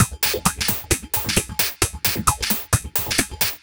132 Skip N Groove Perc.wav